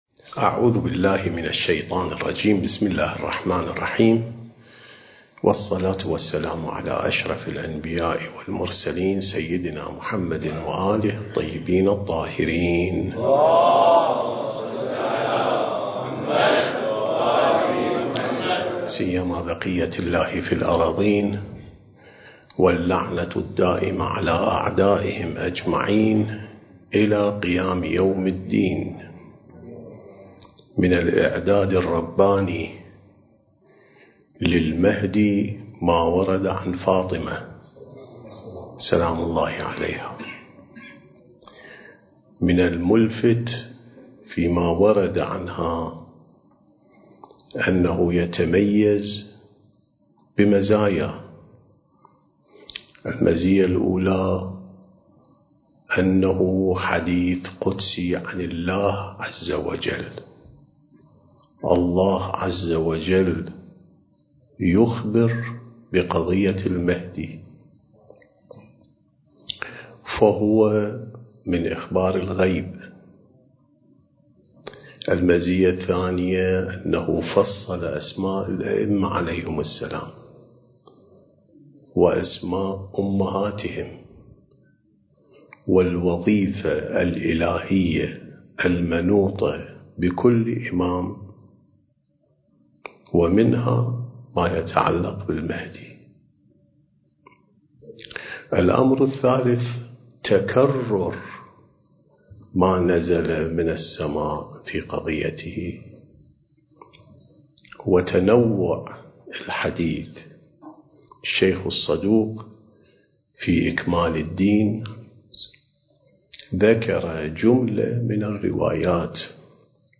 سلسلة محاضرات: الإعداد الربّاني للغيبة والظهور (7)